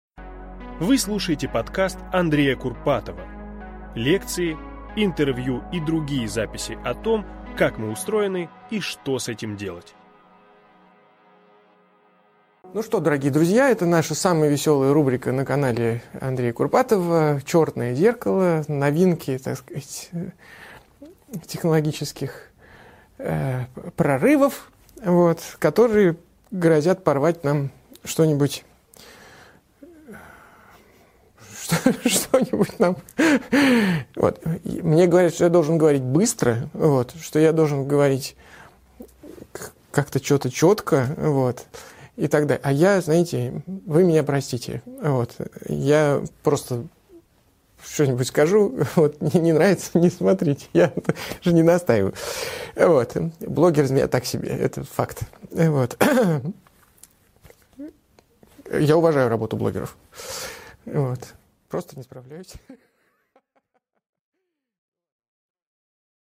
Аудиокнига Мозг с выходом в интернет. Черное зеркало с Андреем Курпатовым | Библиотека аудиокниг